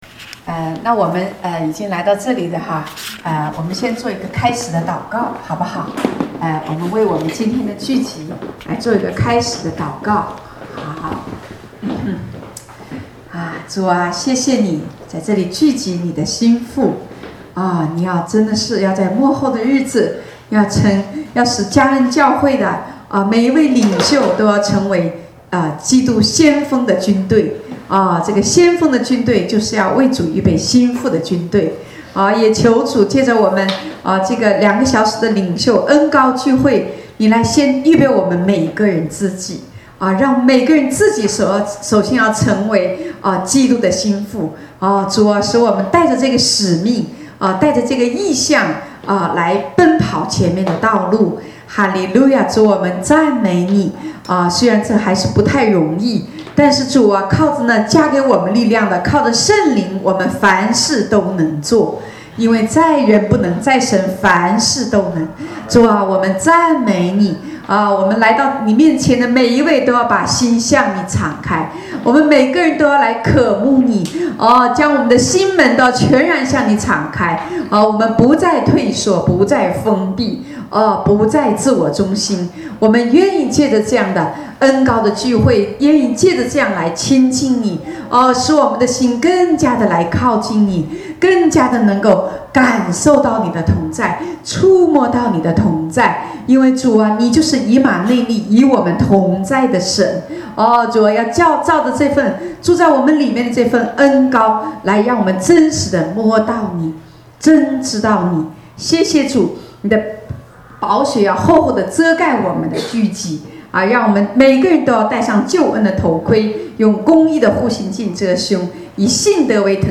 正在播放：--主日恩膏聚会录音（2014-10-12）